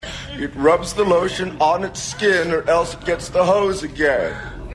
While my ringtone isn’t conventional (which is kind of the point), I’m not worried about it triggering flashbacks and night-terrors in any children who hear it.